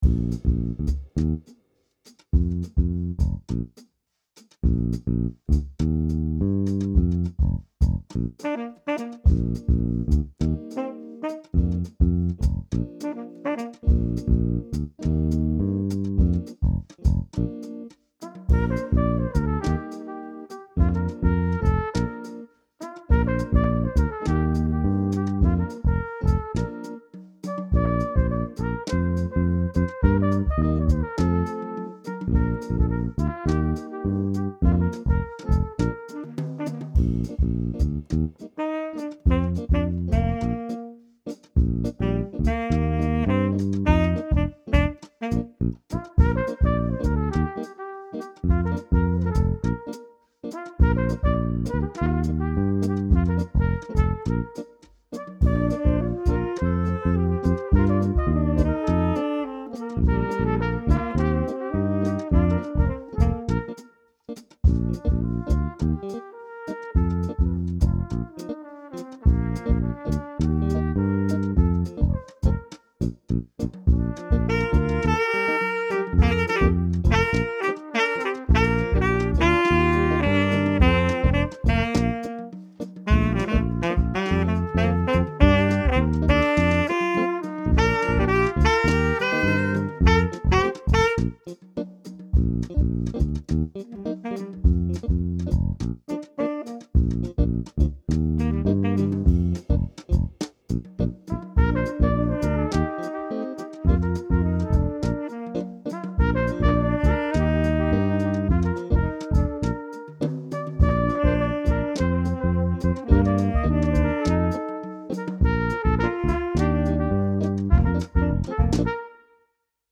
I’ve lately been having fun on weekends by setting up a mic and do some recording. Here are some (unpolished - I’ve only spent an afternoon recording each song) results of that: An acoustic version of Daft Punk’s Around the World, Some Brahms (my parents recently had their 51st wedding anniversary), a traditional wedding march (recorded for my parents’ 50th wedding anniversary), an old Easter hymn and